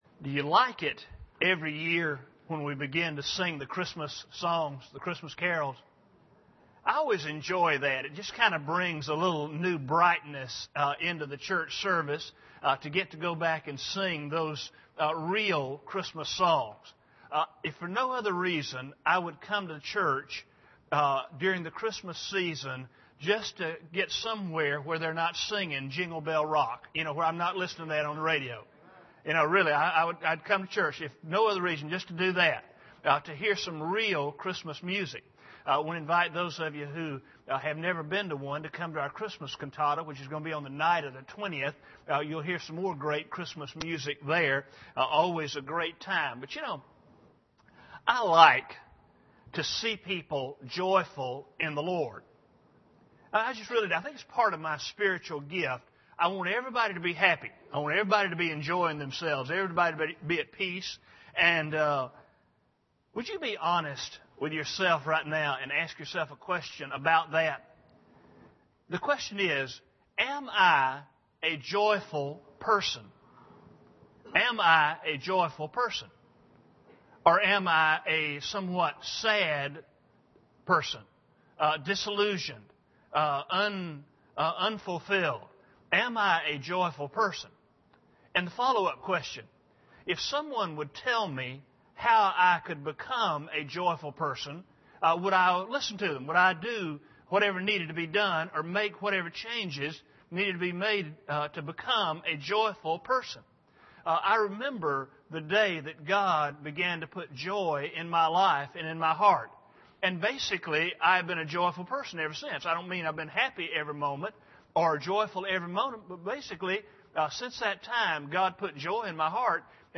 Acts 2:40-46 Service Type: Sunday Morning Bible Text